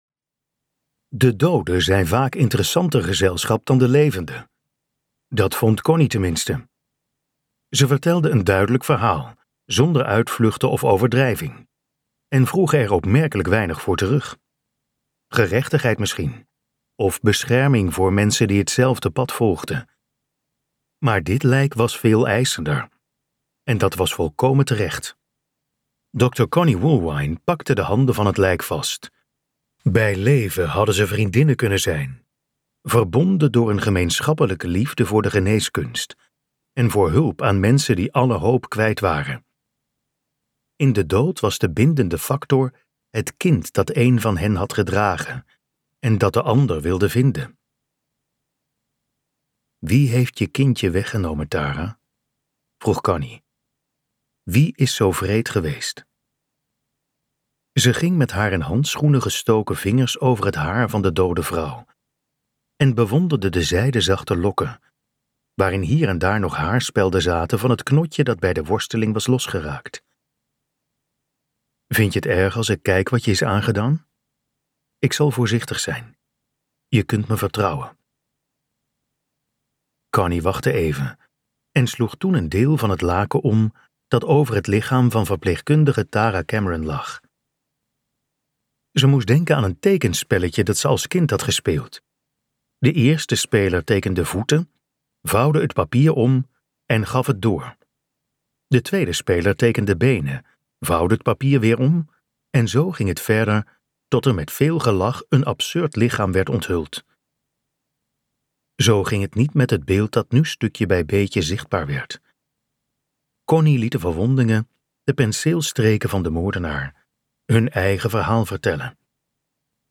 Ambo|Anthos uitgevers - De kliniek luisterboek